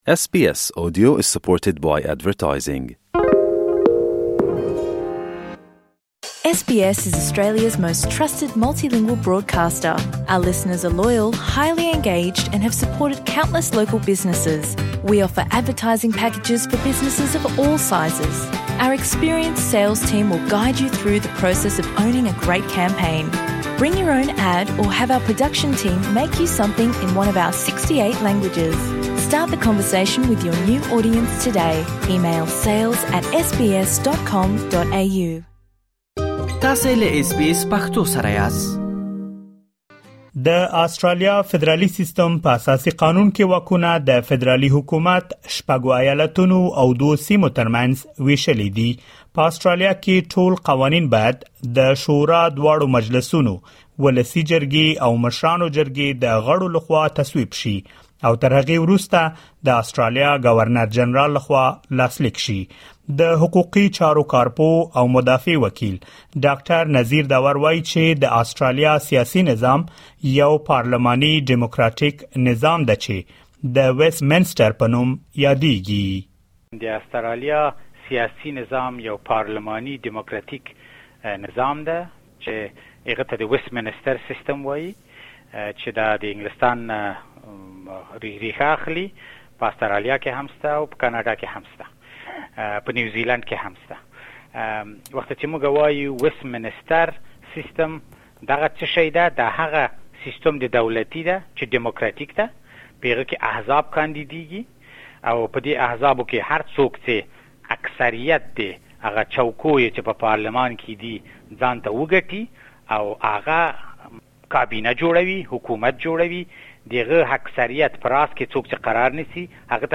د مې میاشتې په ۳مه نېټه په آسټرالیا کې فدرالي ټاکنې ترسره کېږي. په دغه رپوټ کې مو د آسټرالیا د سیاسي نظام په اړه معلومات را غونډ کړي دي.